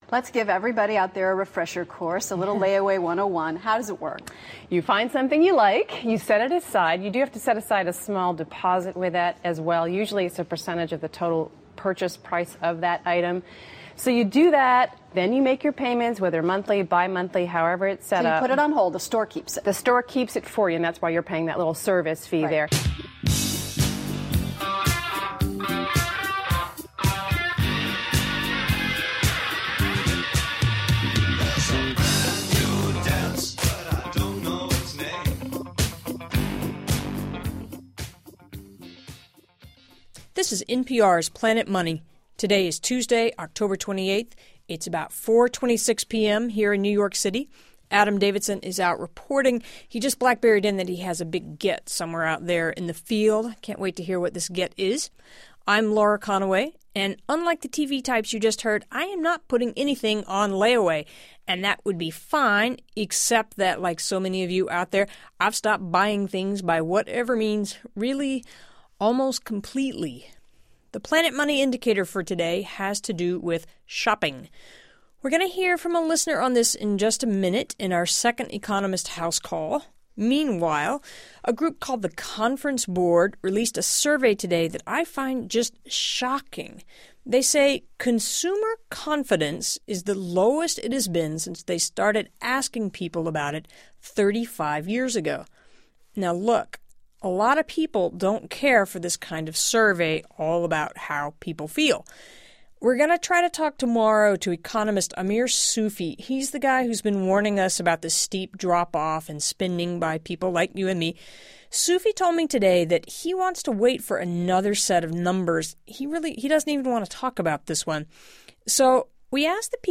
A survey finds consumer confidence at its lowest level in 35 years. An economist walks us through the wreckage. Plus: A guy in Rhode Island says he has stopped spending money. Simon Johnson, economist, diagnoses the trouble with that.